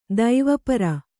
♪ daiva para